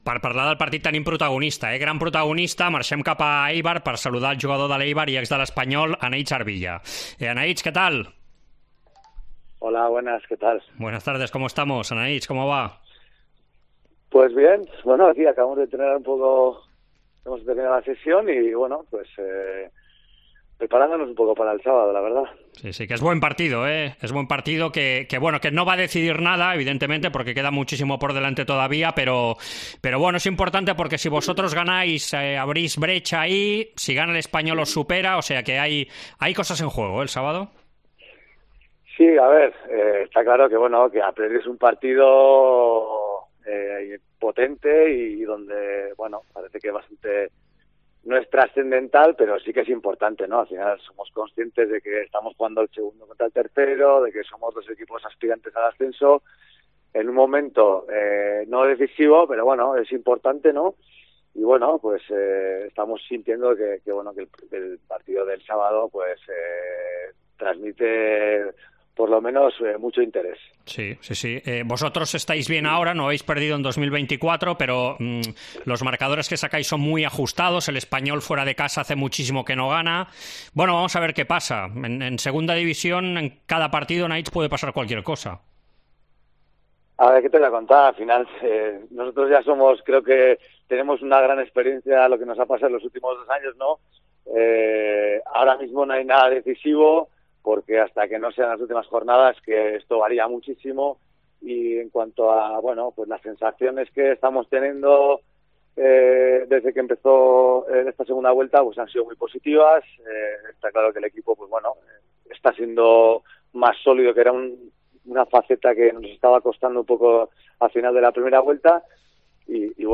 Entrevista Esports COPE